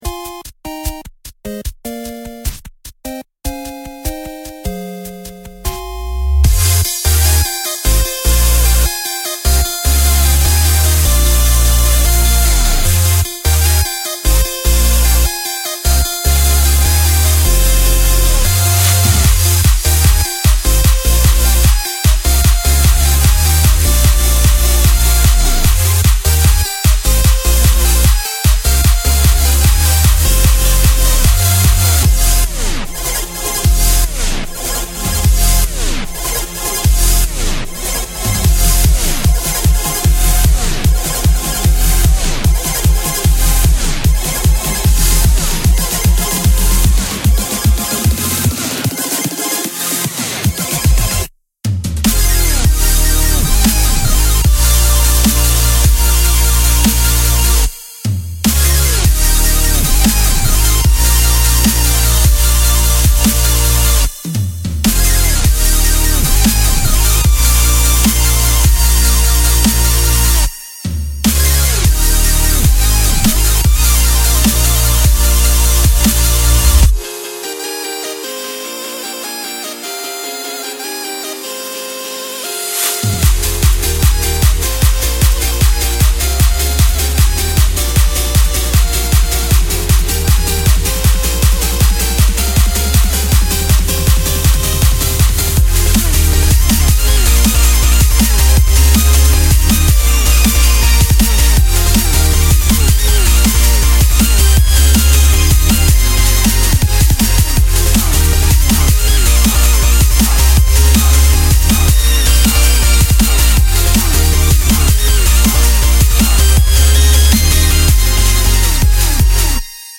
BPM150-160
MP3 QualityMusic Cut
chiptune song